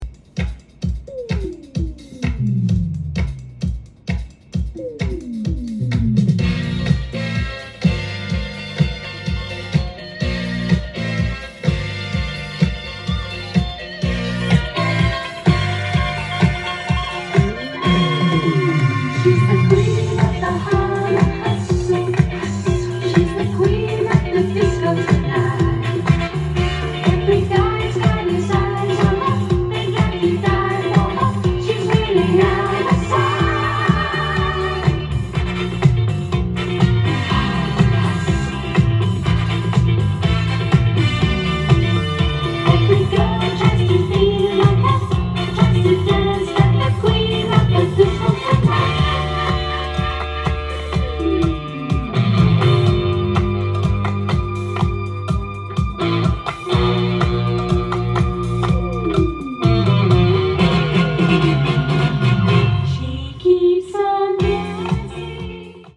店頭で録音した音源の為、多少の外部音や音質の悪さはございますが、サンプルとしてご視聴ください。
音が稀にチリ・プツ出る程度